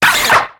Cri de Coupenotte dans Pokémon X et Y.